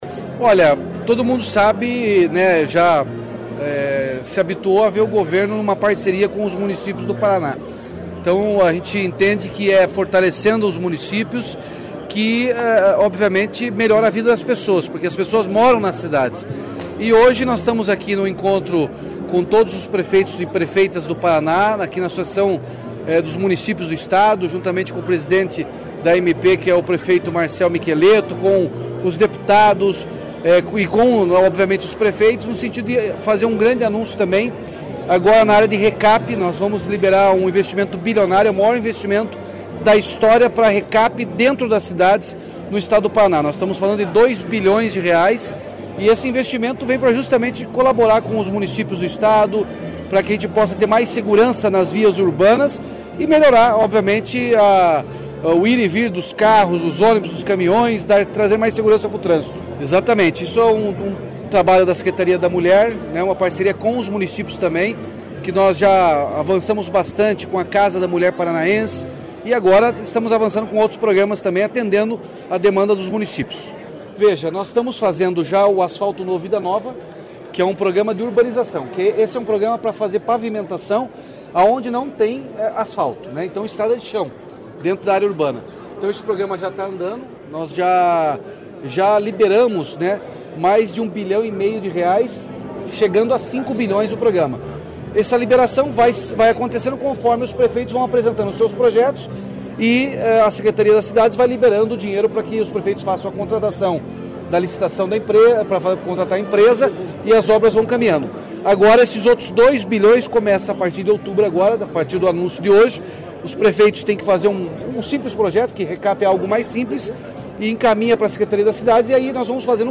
Sonora do governador Ratinho Junior sobre o repasse de R$ 2 bilhões para os municípios para projetos de recapeamento asfáltico